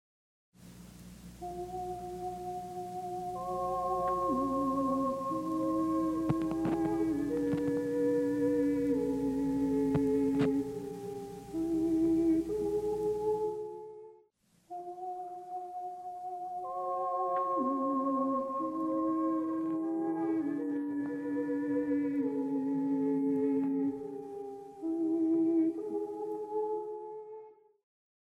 The recording being restored is of a choir singing in a chapel. Unfortunately, the analog tape recorder that was used had circuitry that was malfunctioning, and various noise spikes and crackles were superimposed over the music.
We hear a short segment before restoration and after. Note that the "after" portion has been subject to broadband noise reduction as well, to reduce the analog tape hiss. The spectral repair is very good but not perfect--a slight trace of the original noise disturbance remains.